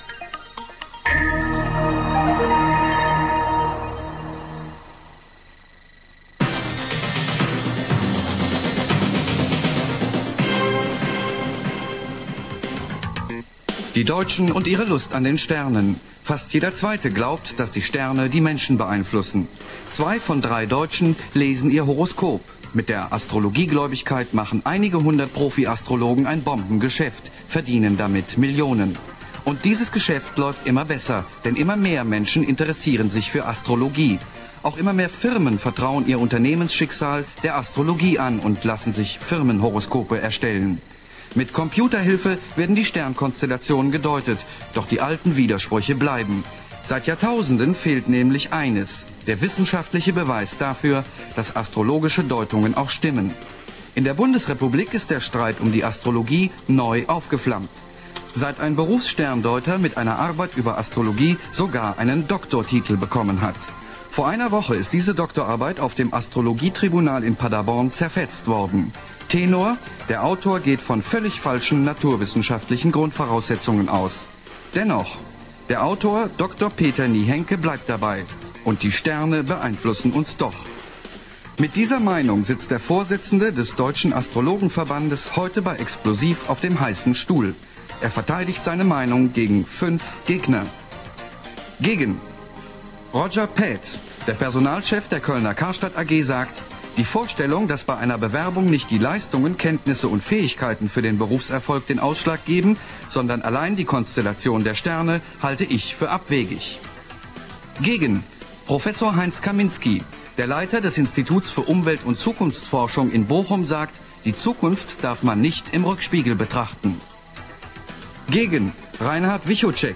A. Intro (die ersten drei Minuten mit Vorstellung der Gäste)